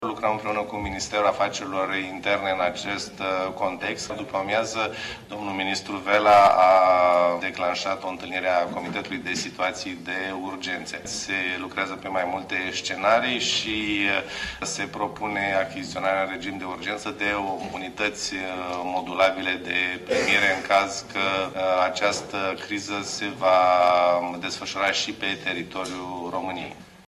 Audiat în comisia de sănătate din Parlament, Victor Costache a fost întrebat cum va interveni Ministerul Sănătății în criza migranților: